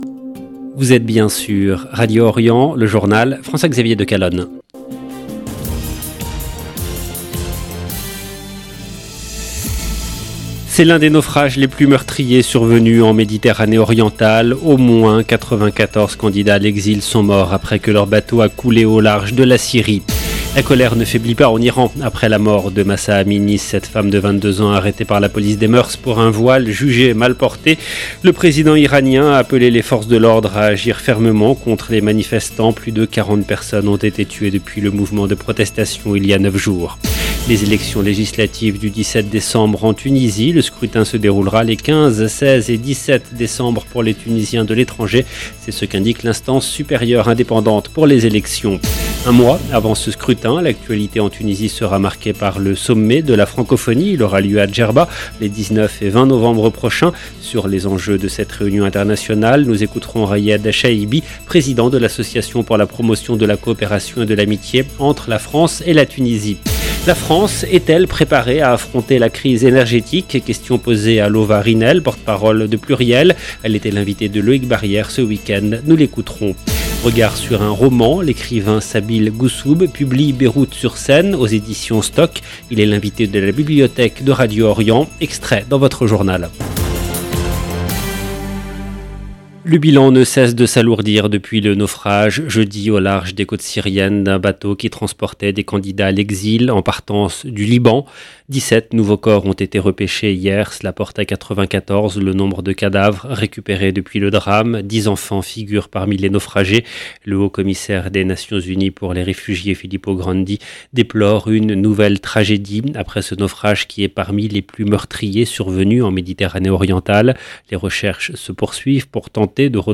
LE JOURNAL DU SOIR EN LANGUE FRANCAISE DU 25/9/2022